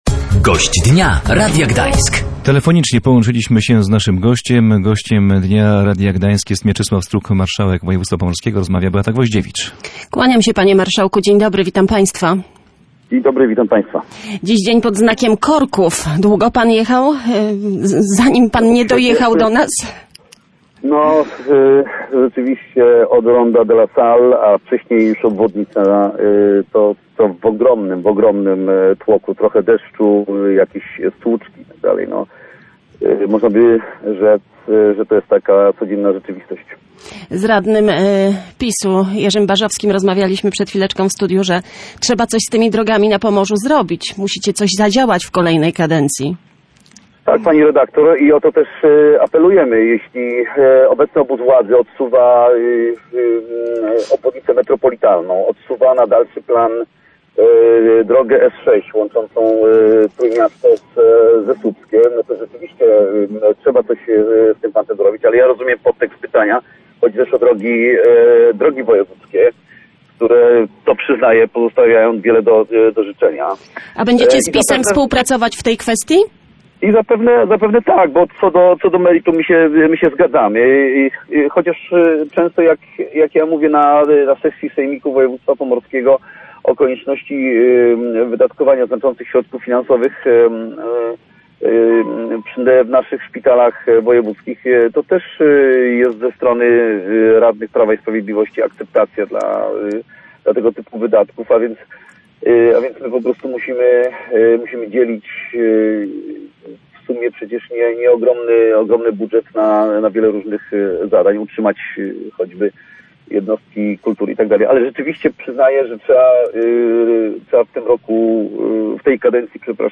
O to w ramach Gościa Dnia Radia Gdańsk zapytaliśmy marszałka województwa pomorskiego Mieczysława Struka.